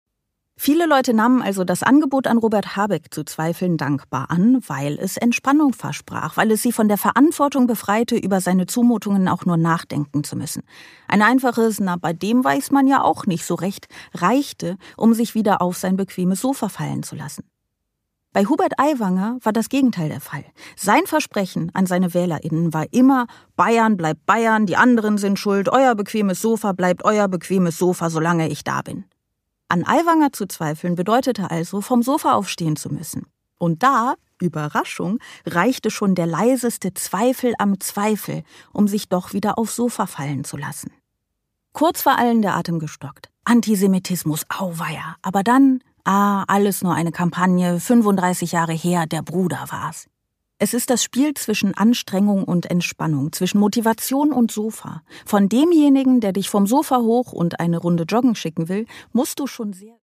Produkttyp: Hörbuch-Download
Gelesen von: Sarah Bosetti